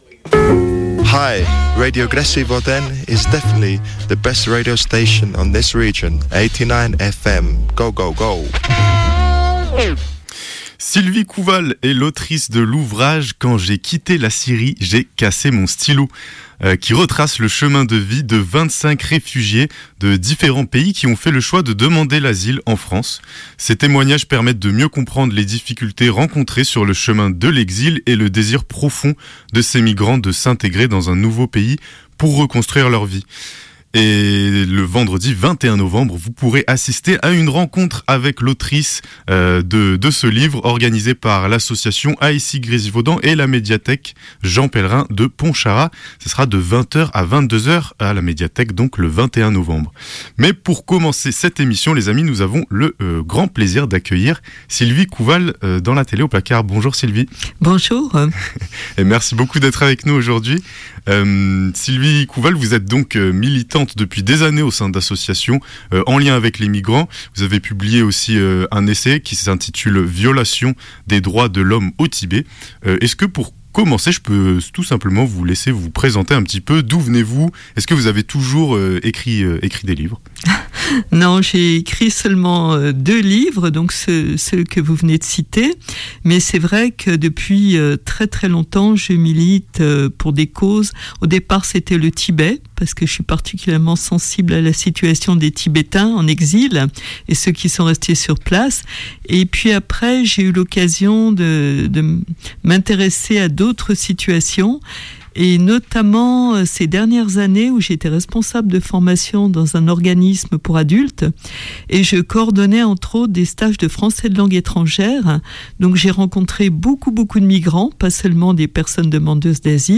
A l’antenne
Interview